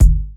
Kick13.wav